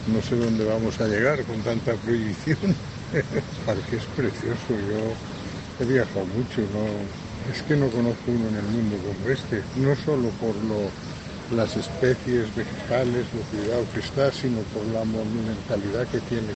vecino del barrio y contrario al cierre de El Retiro